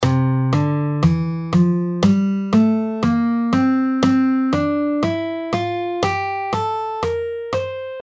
C Mixolydian
C Mixolydian: C – D – E – F – G – A – B♭ – C. Resembling the major scale but with a flattened seventh, often used in blues and rock.
C-MixoLydian-5th-Mode-Of-C-Major.mp3